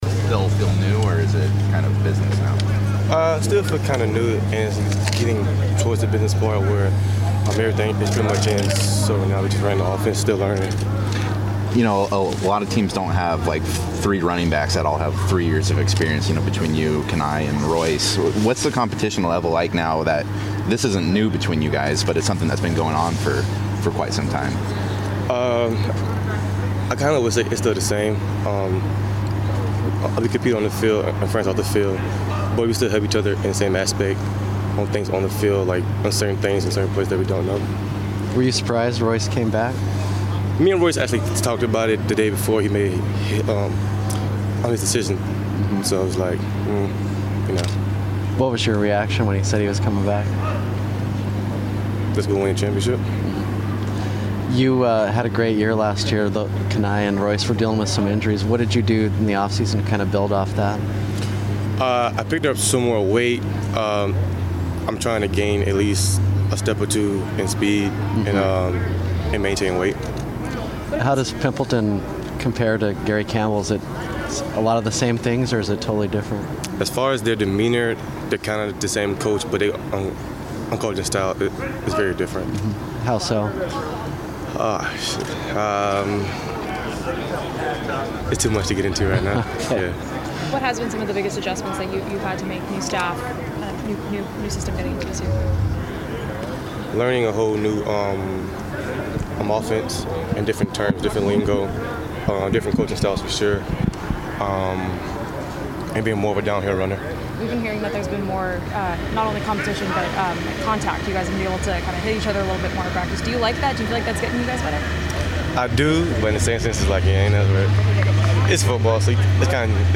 speaks with the media before practice.